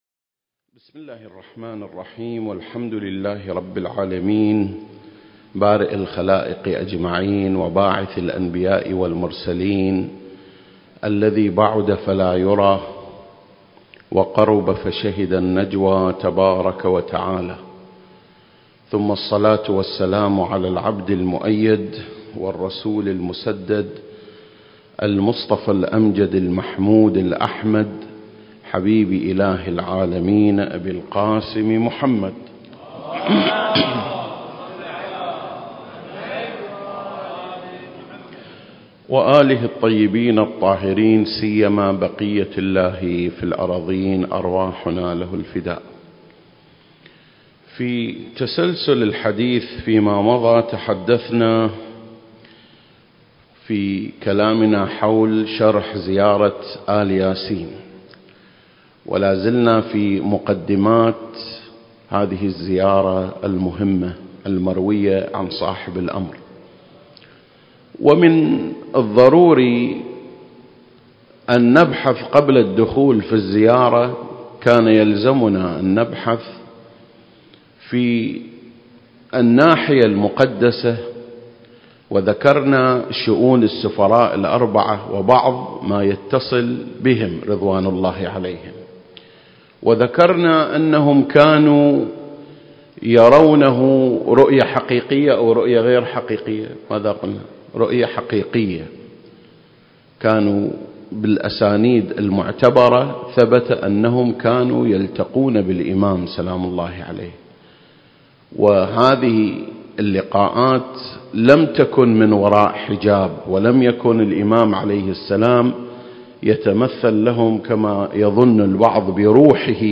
المكان: مسجد مقامس - الكويت